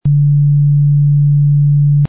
MARS144.72Hz
144.72 Hz (32.05 KHz sampling rate, 8 Bit)
Tone: 144.72 Hz = D
the difference to 440 Hz.. = ...– 25.1 cent